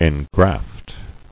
[en·graft]